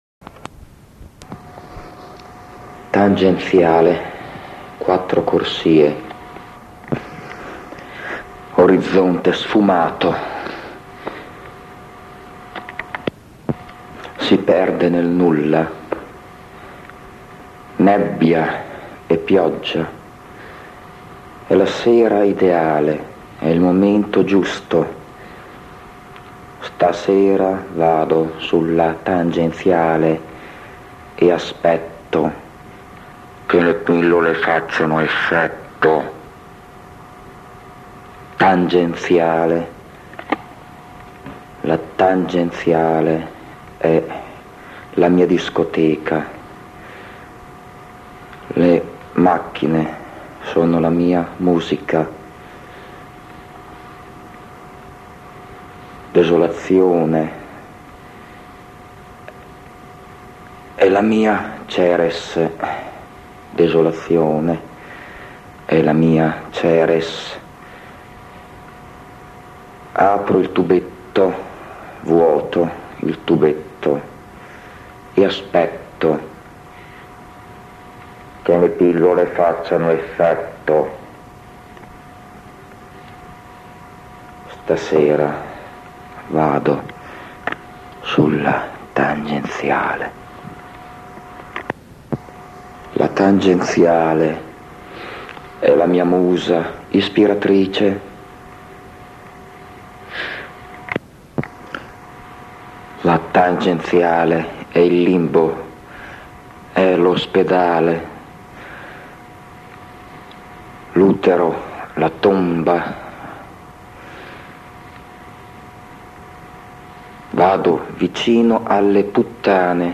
sono qui raccolti alcuni pezzi estratti dalle registrazioni su nastro dei primi anni '90.